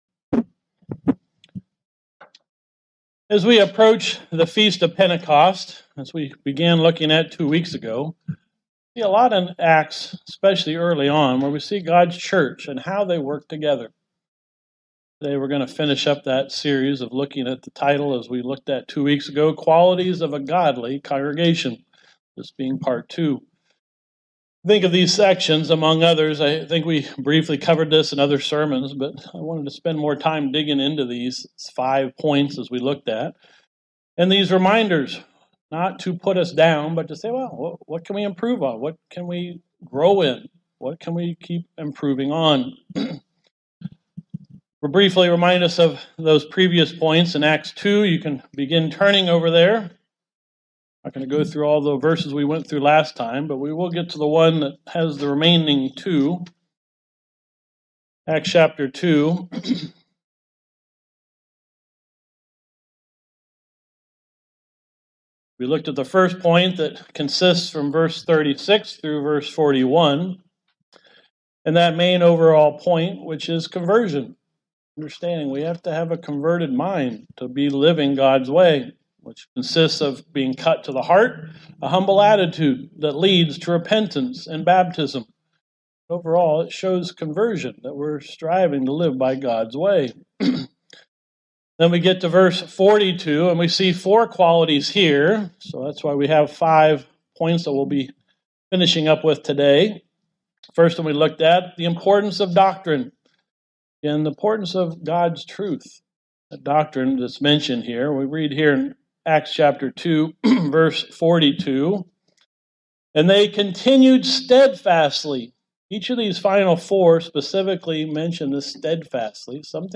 Sermons
Given in Elkhart, IN